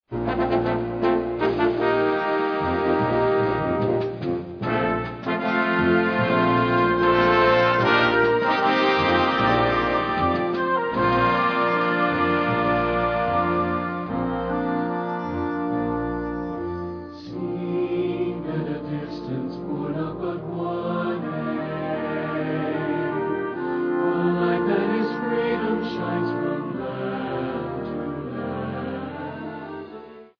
Besetzung: Blasorchester
für Blasorchester und Chor